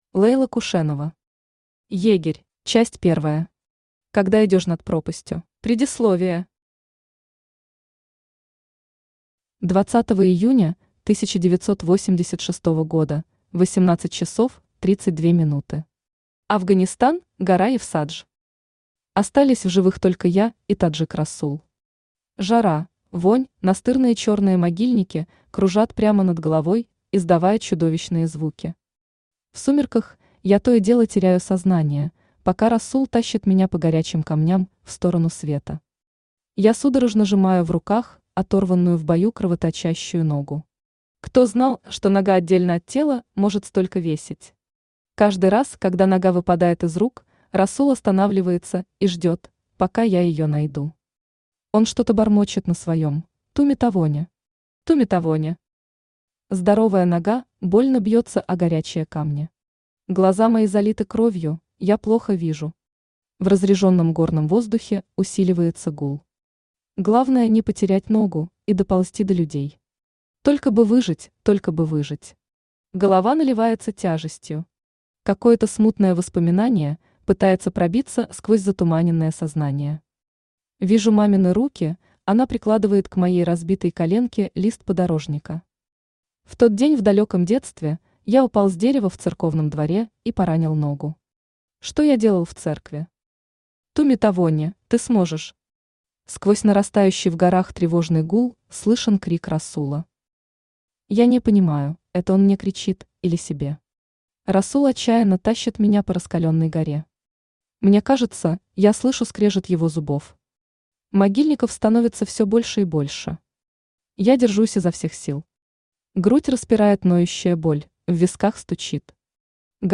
Aудиокнига Егерь Автор Лейла Кушенова Читает аудиокнигу Авточтец ЛитРес.